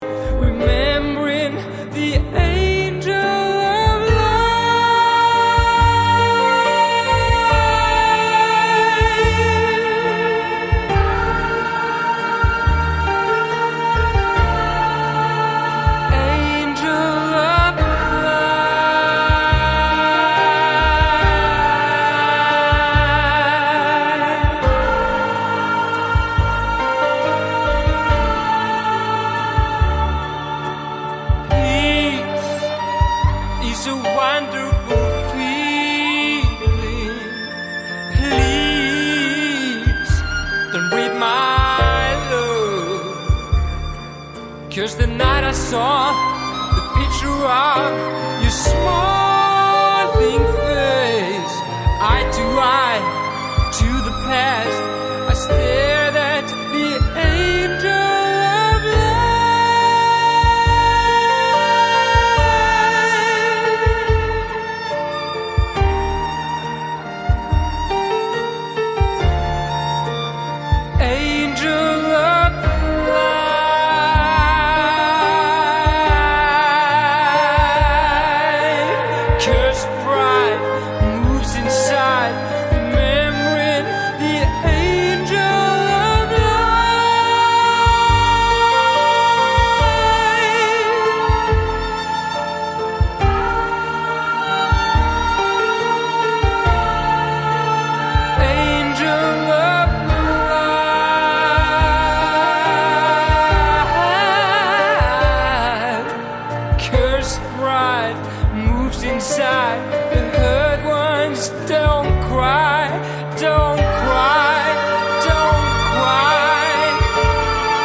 solo cut version